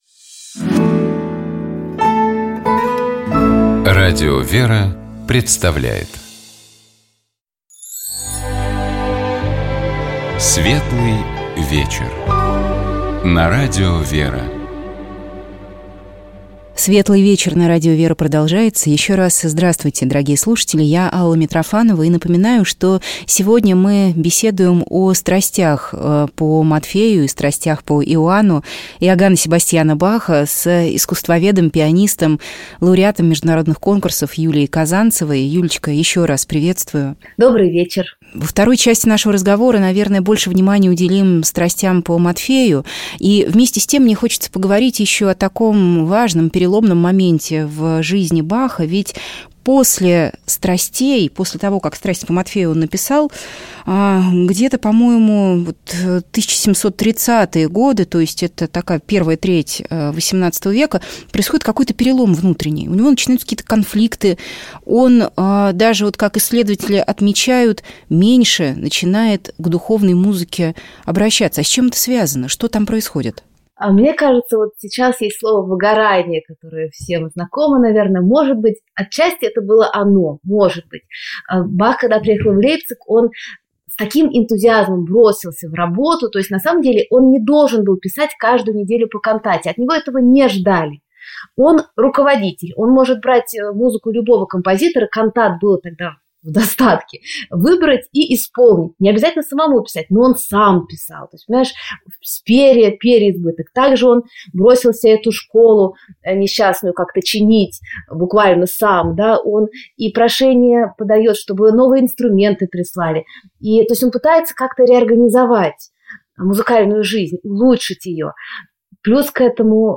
Мы беседовали с кандидатом искусствоведения, пианисткой